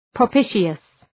Προφορά
{prə’pıʃəs}
propitious.mp3